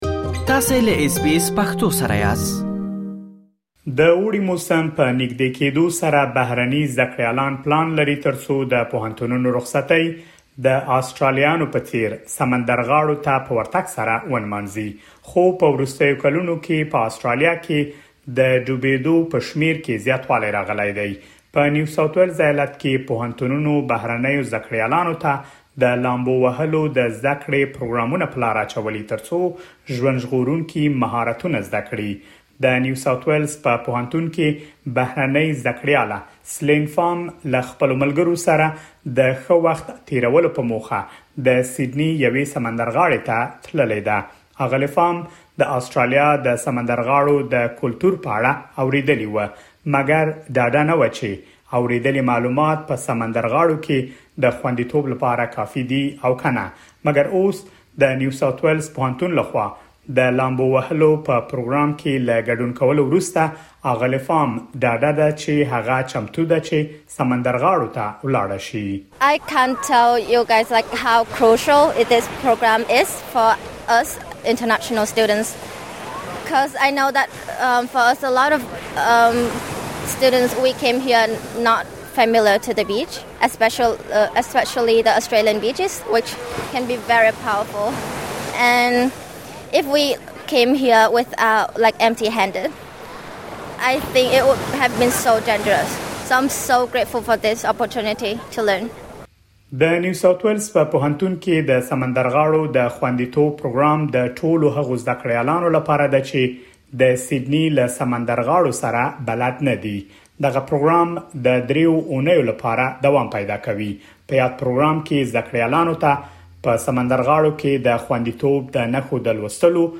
په نیو ساوت وېلز ایالت کې پوهنتونونو بهرنیو زده کړیالانو ته د لامبو وهلو د زده کړې پروګرامونه په لاره اچولي ترڅو ژوند ژغورونکي مهارتونه زده کړي. مهرباني وکړئ لا ډېر معلومات په رپوټ کې واورئ.